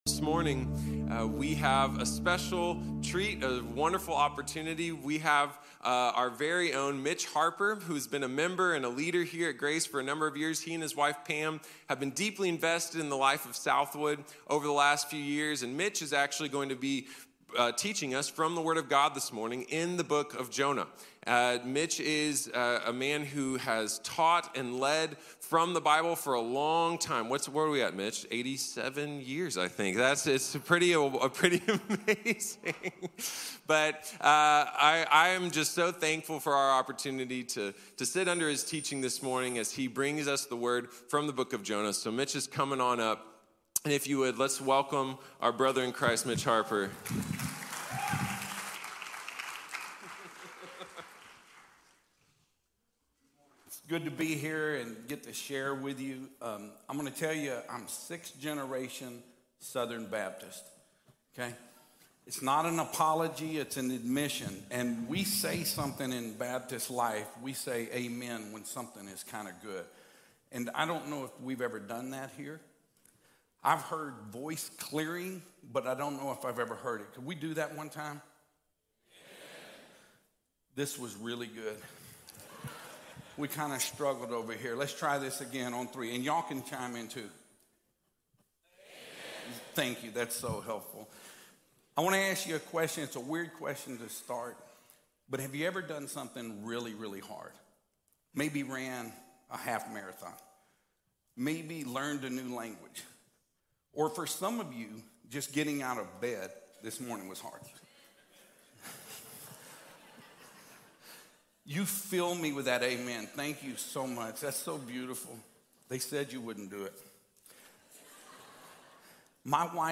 Jonah - In and Through | Sermon | Grace Bible Church